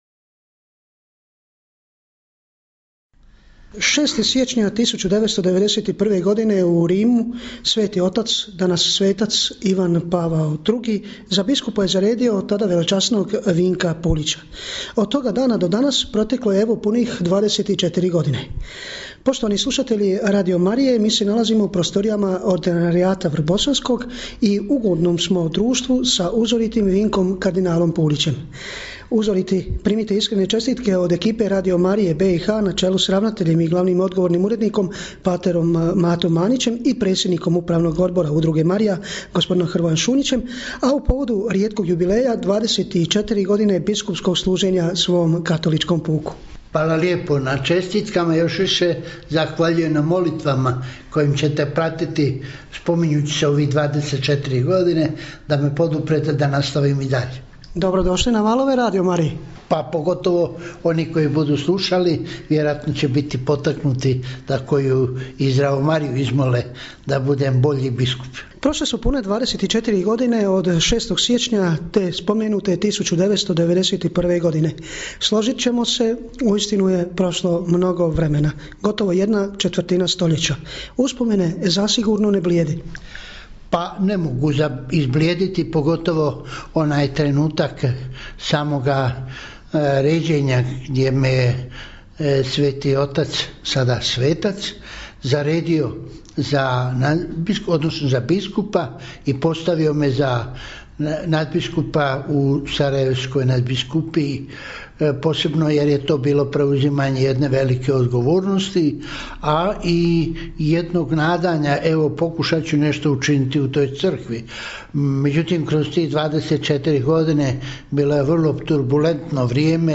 AUDIO: Intervju kardinala Vinka Puljića za u povodu 24. obljetnice biskupskog ređenja
Intervju koji je objavljen u utorak, 6. siječnja 2014. u 20 sati u programu Radio Marije BiH, možete poslušati u prilogu: